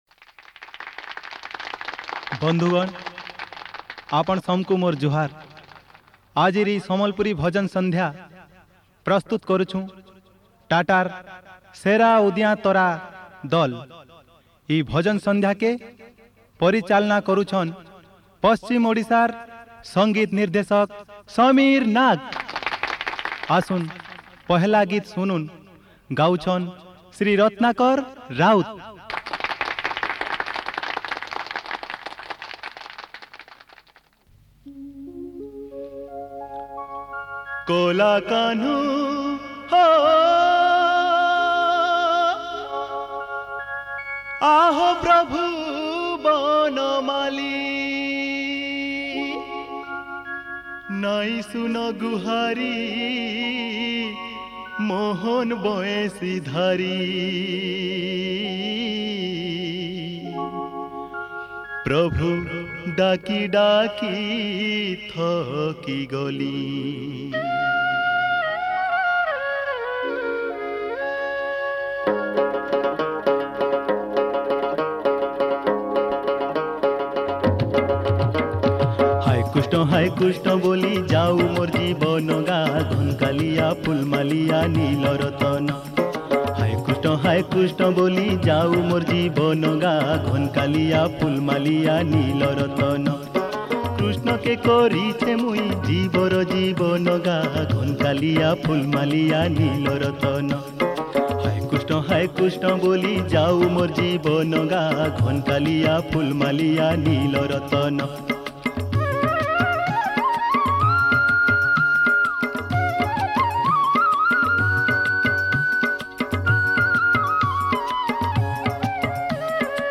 Category: Sambalpuri Bhajan Sandhya